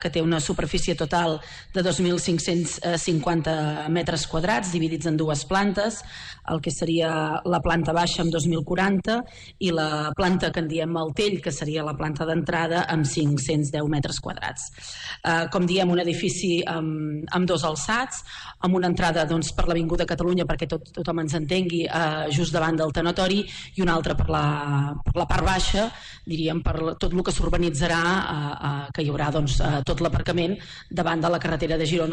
Ho explica la regidora d’Empresa, Turisme i Economia local, Núria Cucharero.